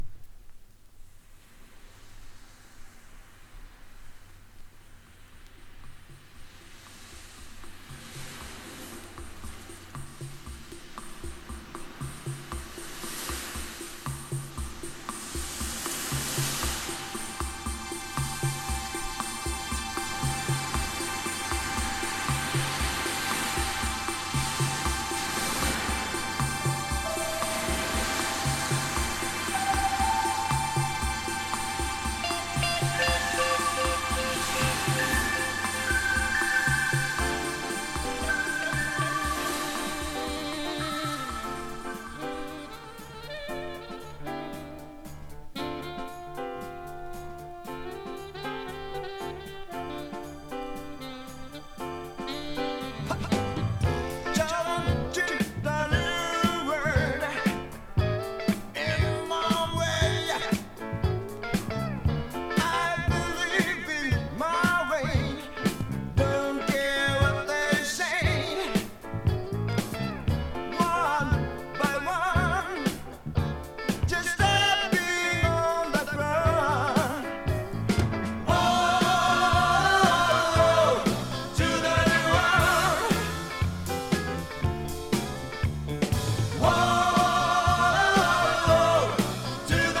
# ロック名盤# 60-80’S ROCK
ロックはもちろん、ファンク、レゲエ、色々なジャンルのテイストを吸収したオリジナルなロックで素晴らしいです！！